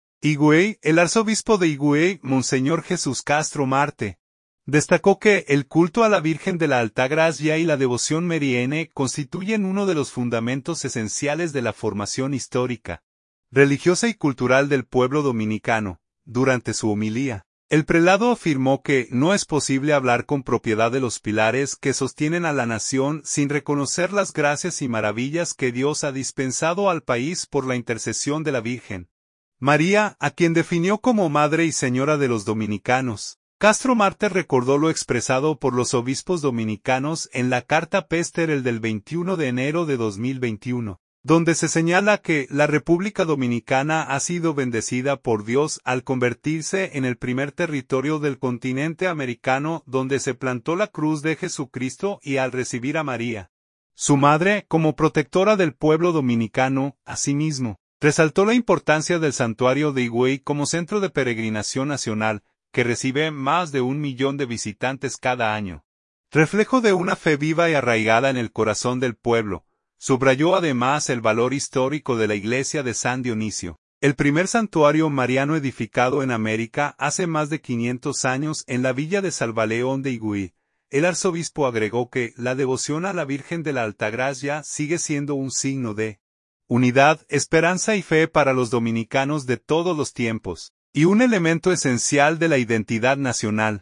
Durante su homilía, el prelado afirmó que no es posible hablar con propiedad de los pilares que sostienen a la nación sin reconocer las gracias y maravillas que Dios ha dispensado al país por la intercesión de la Virgen María, a quien definió como Madre y Señora de los dominicanos.